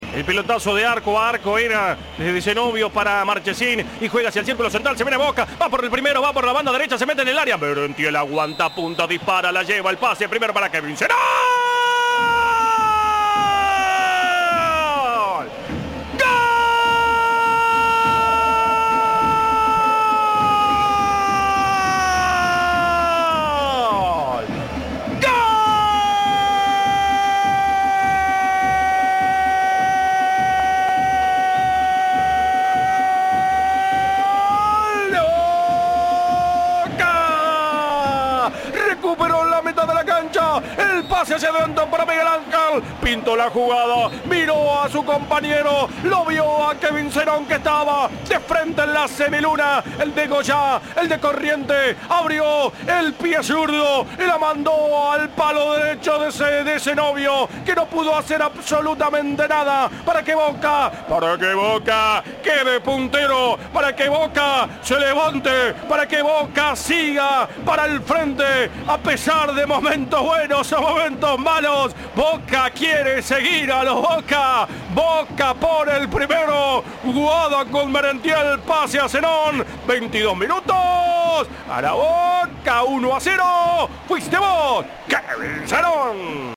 entre lágrimas